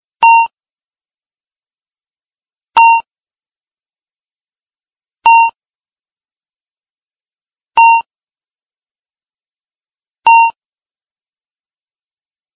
Dzwonek - Odliczanie bez słów
Standardowy dzwięk odliczania przy zbliżaniu się do startu np. programu.
odliczanie.mp3